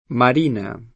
vai all'elenco alfabetico delle voci ingrandisci il carattere 100% rimpicciolisci il carattere stampa invia tramite posta elettronica codividi su Facebook Marina [ mar & na ; ingl. mër & inë ; sp. mar & na ] pers. f. — cfr.